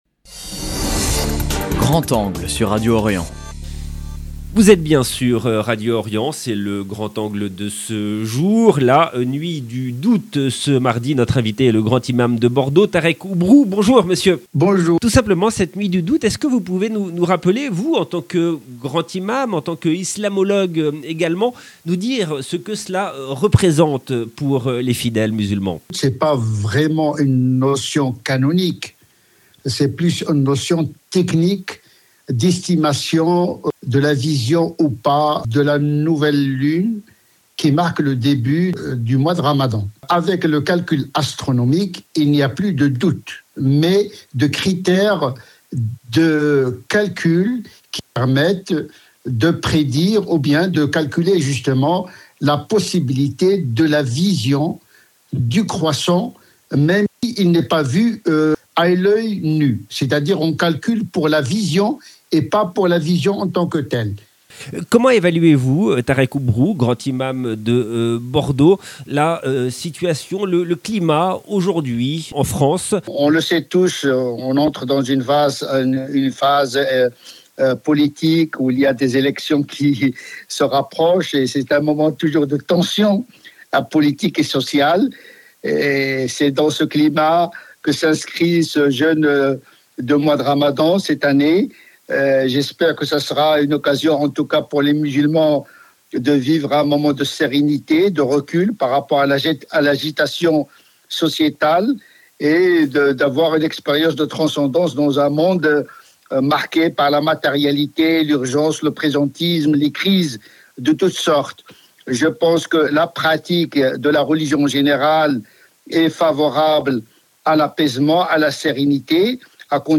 L’annonce depuis la Grande mosquée de Paris de la date du début du mois du Ramadan. Le Grand imam de Bordeaux Tareq Oubrou est l’invité de Grand angle. 0:00 9 min 44 sec